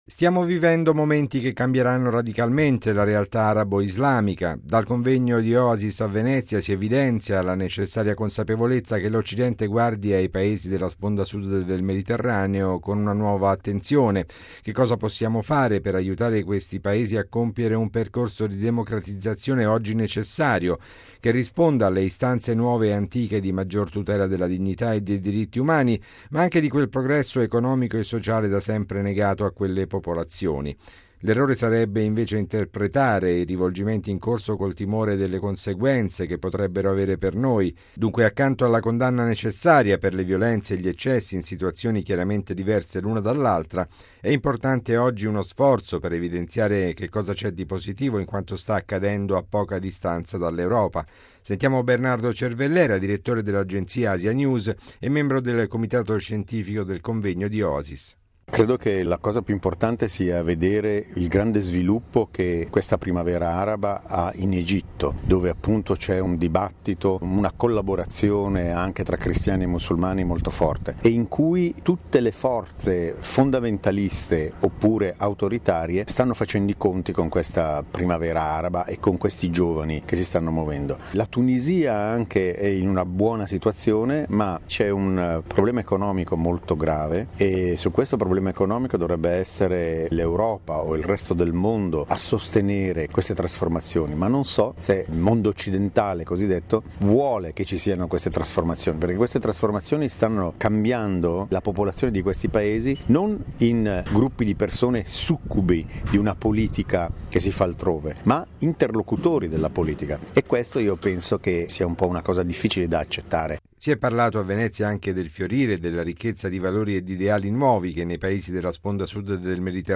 ◊   Si conclude oggi a Venezia il convegno internazionale “Medio Oriente verso dove? Nuova laicità e imprevisto nord-africano”, organizzato dalla Fondazione Oasis e promosso dal presidente dell’organismo, il cardinale patriarca di Venezia, Angelo Scola.